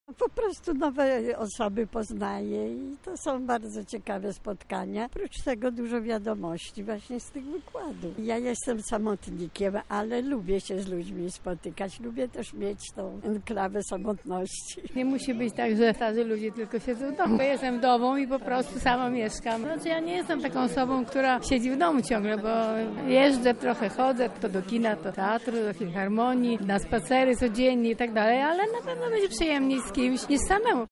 Zapytaliśmy seniorów, co sądzą o takim miejscu: